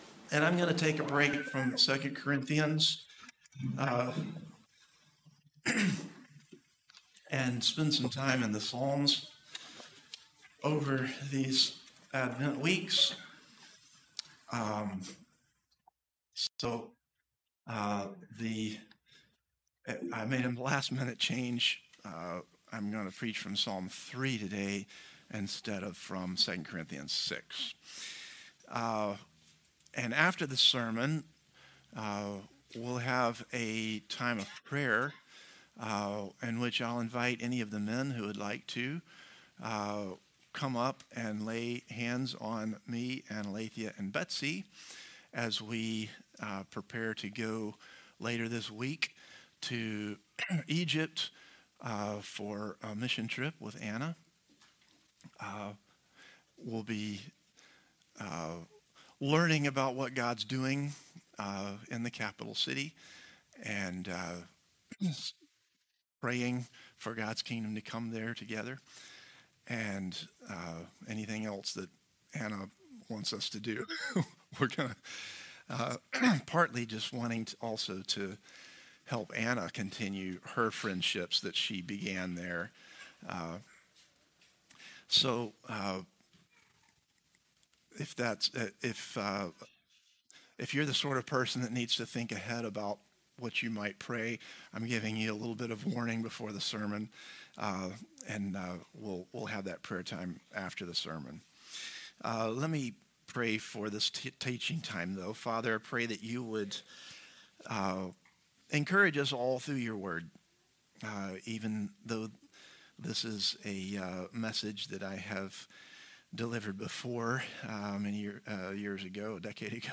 Christ the Redeemer Church | Sermon Categories Fear